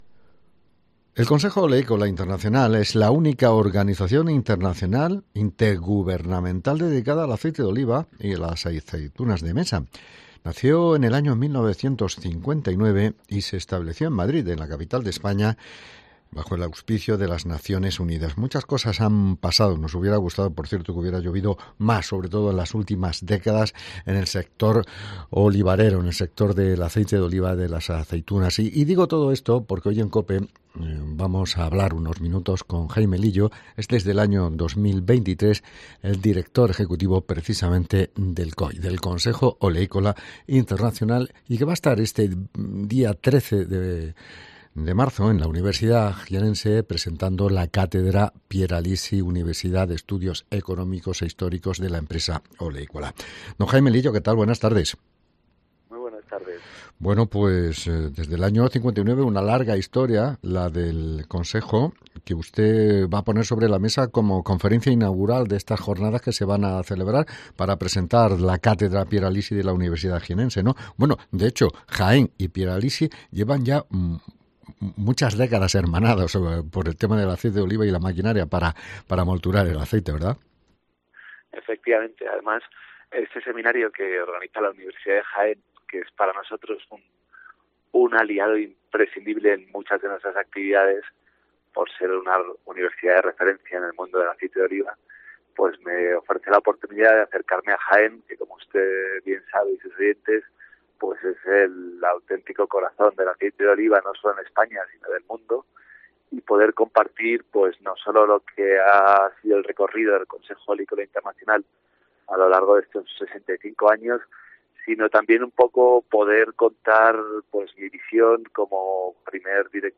Entrevista con Jaime Lillo, Director Ejecutivo del COI